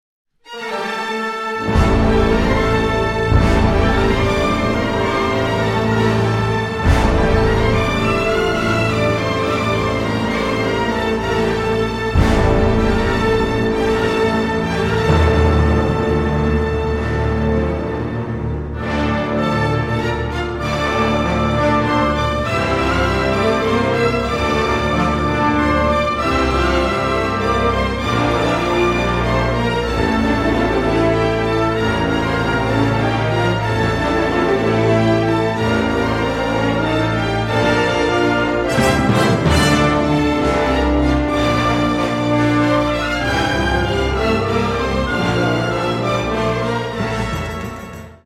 • Spectacular New Digital Recording